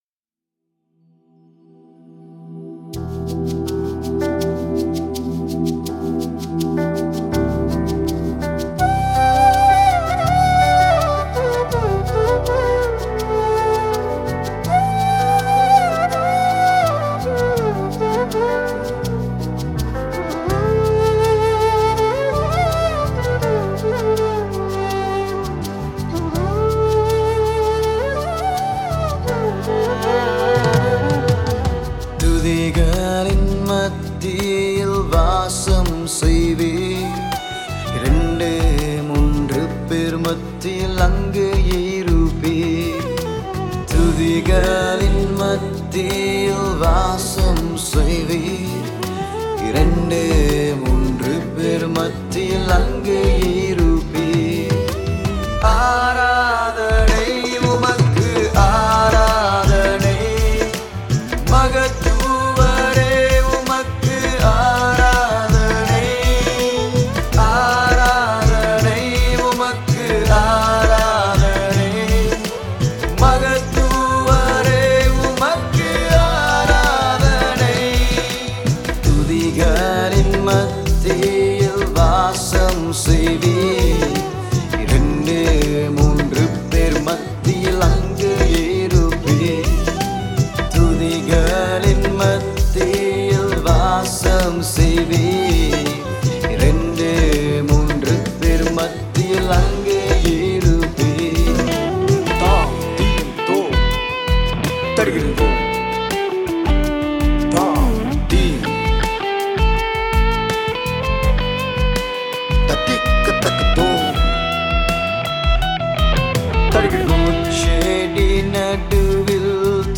Guitar
Bass Guitar
Flute
Backing Vocal